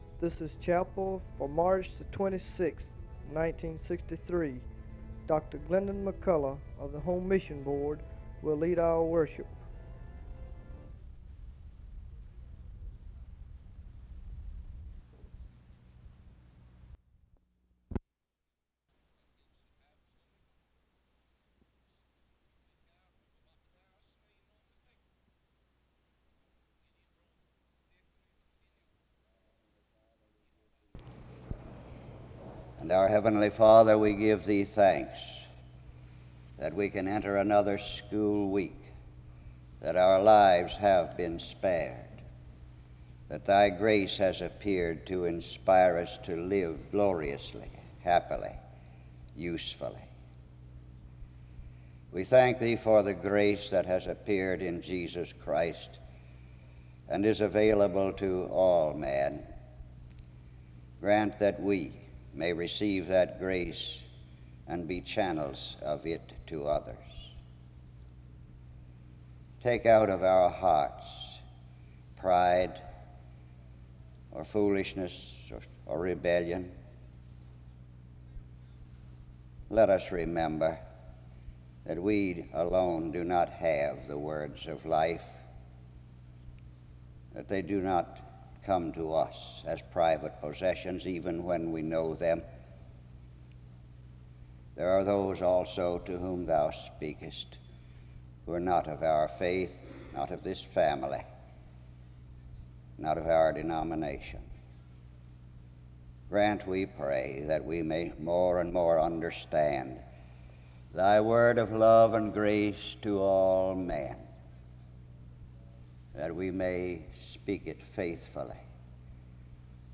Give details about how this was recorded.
Archive Work | SEBTS Chapel